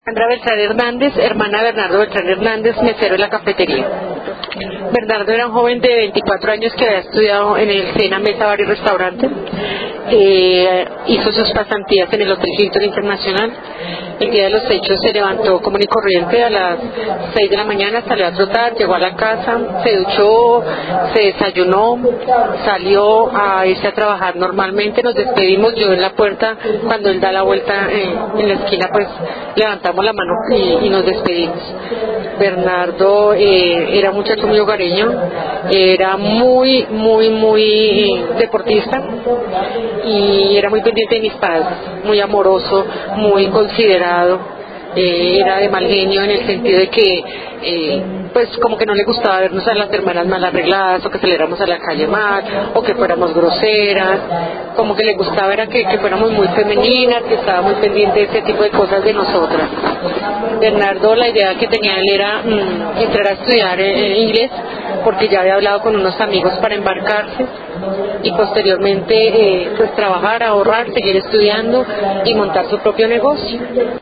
A través de las palabras y voces de los  familiares de las víctimas, recordamos a cada uno de los desaparecidos del Palacio de Justicia: